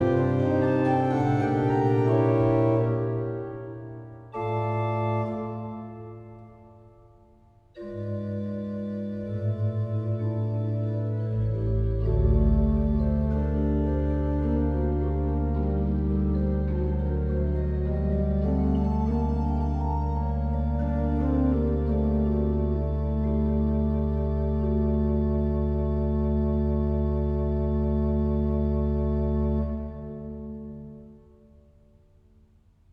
son jeu souvent très rapide et toujours dynamique